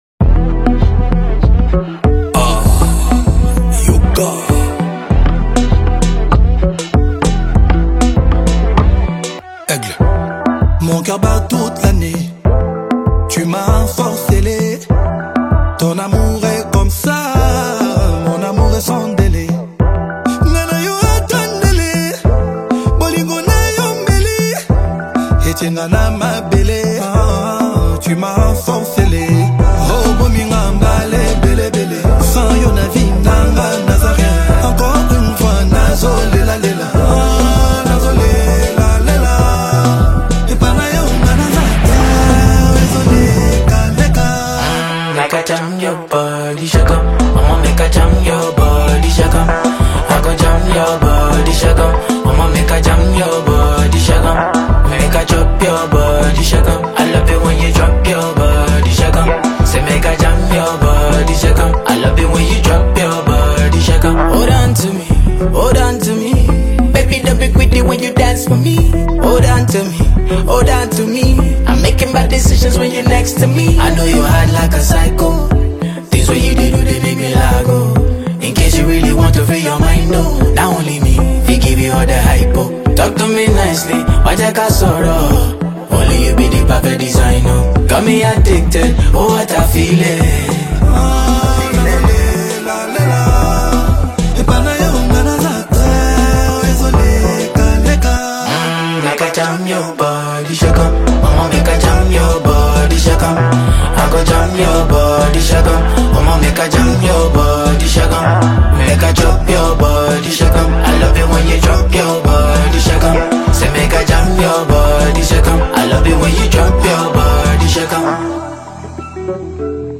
delivers his signature smooth vocals
provides a laid-back, melodic flow